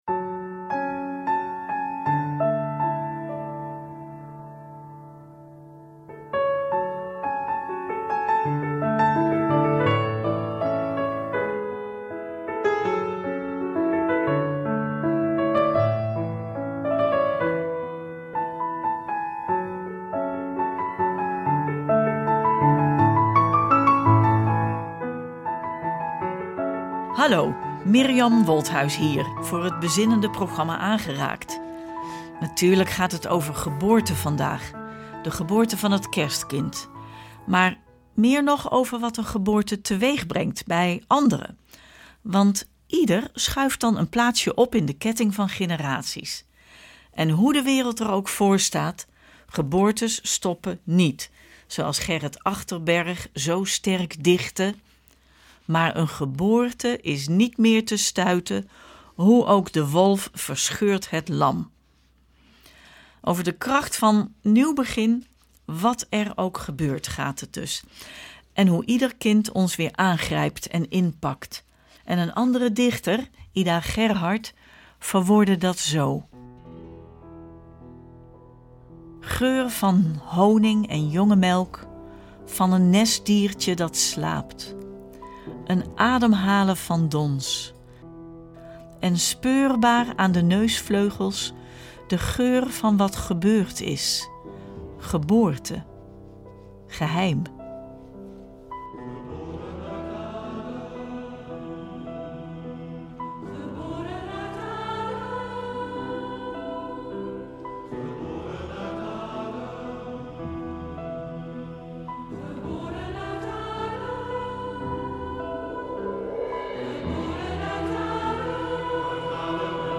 Over de kracht en hoop van een geboorte, en die van het Kind Gods bovenal, gaat deze uitzending van Aangeraakt aan de vooravond van Kerstmis 2025. Ida Gerhardt zal klinken, voormalig generaal Peter van Uhm en muziek die stemmig is en aanmoedigend tegelijk, want er is geen ontkomen aan die geboorte.